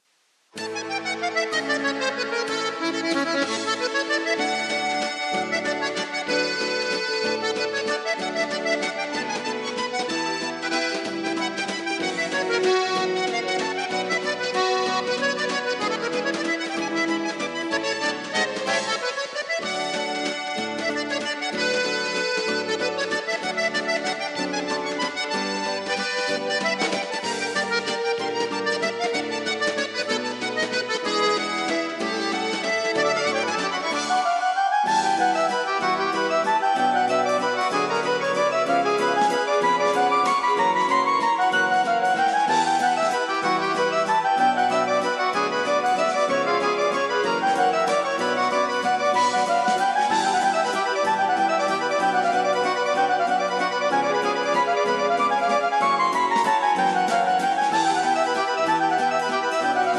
(Valse à variations)